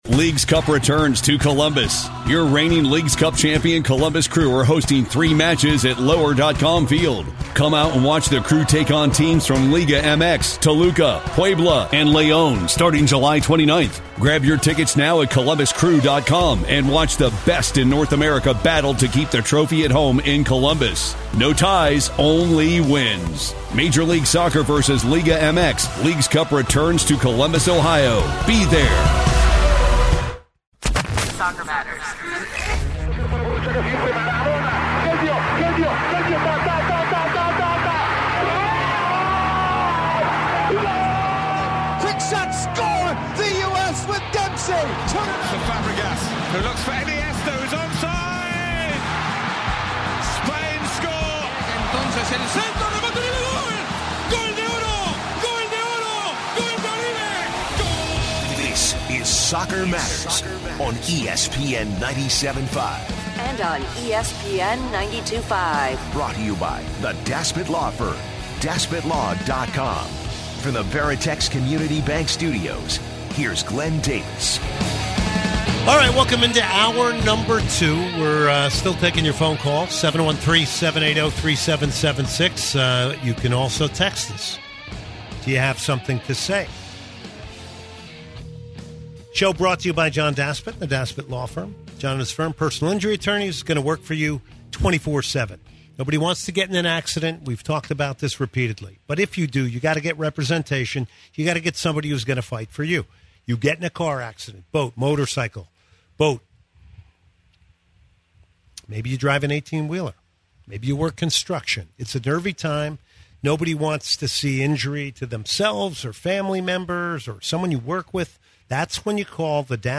interview with Tab Ramos, to talk on his new job as Hartford Athletic Head Coach, the club it self, USL , & more.